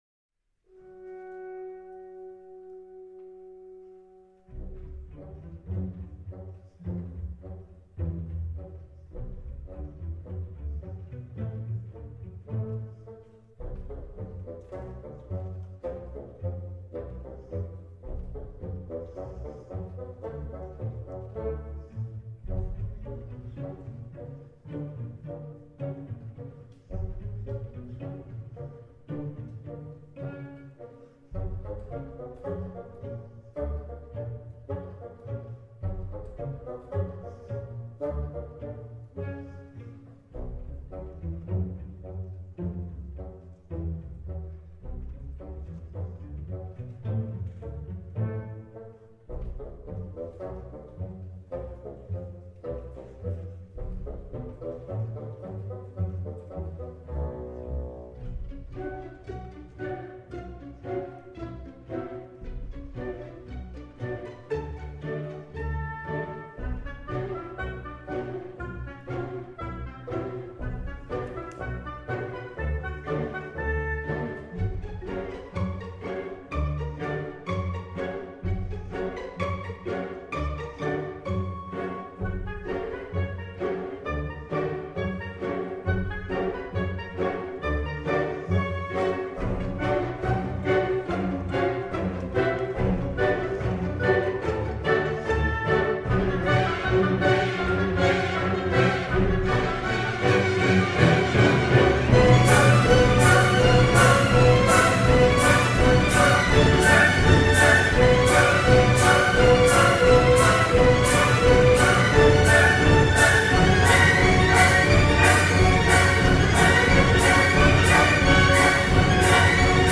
Today, we are going to learn about music that makes us feel SCARED.